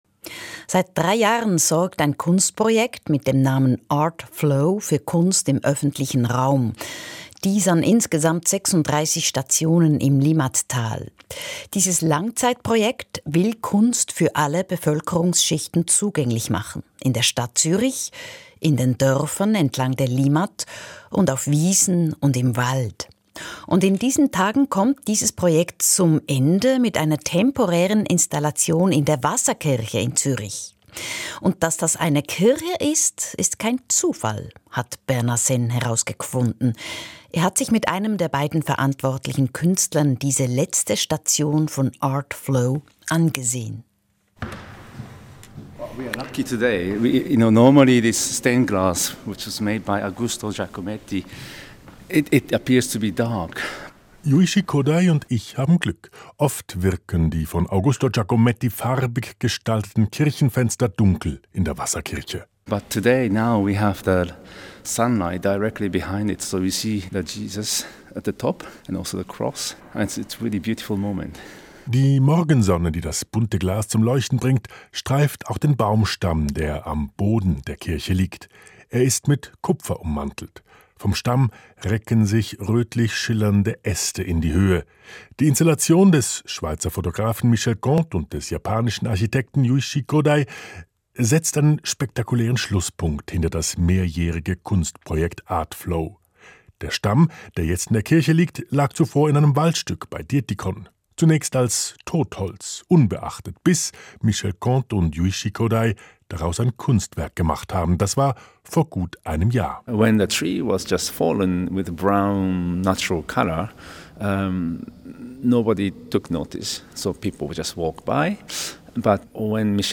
Radio: SRF2 Kultur, 24.12.2025